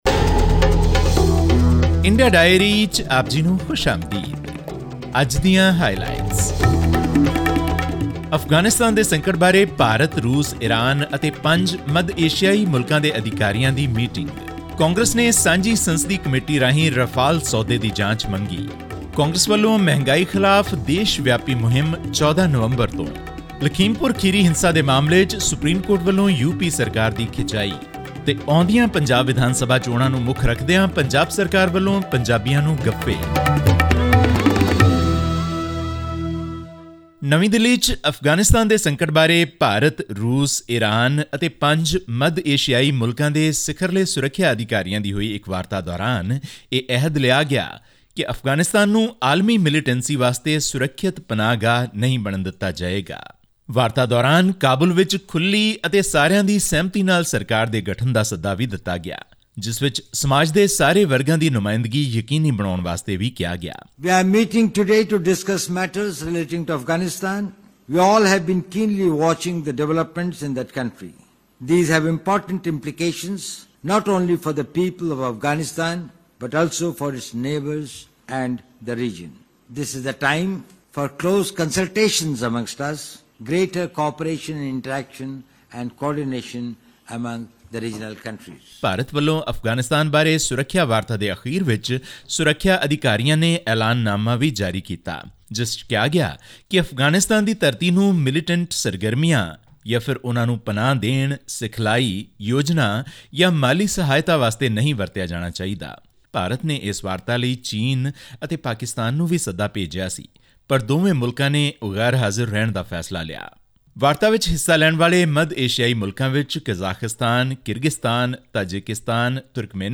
Unhappy over the probe into the Lakhimpur Kheri incident in which eight people, including four farmers, were killed, the apex court on 8 November said it wanted to appoint a retired judge to monitor it till filing of chargesheet in the case. All this and more in our weekly news segment from India.